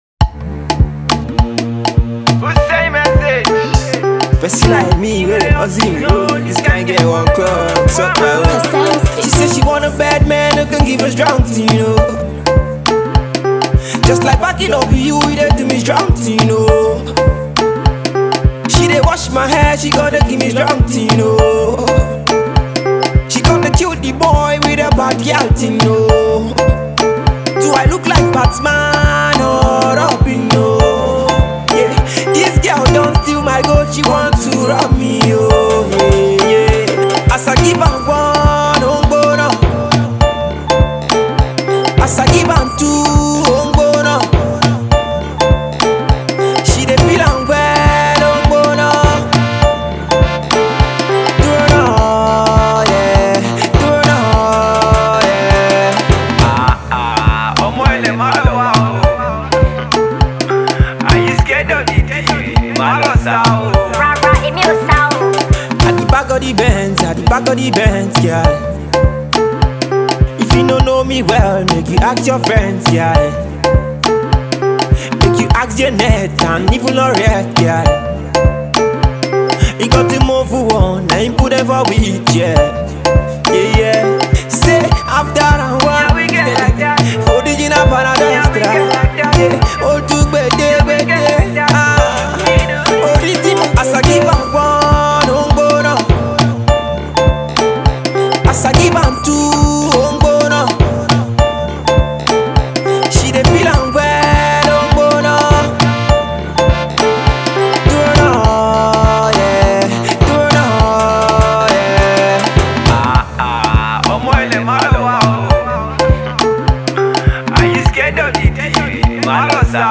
Afro-pop
hot afro-fusion sound